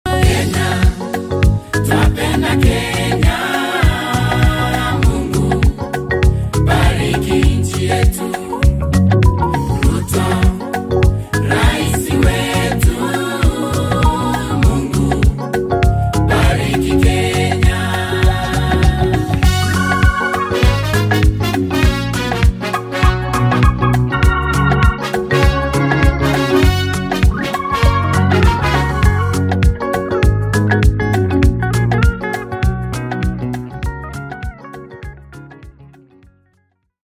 Cultural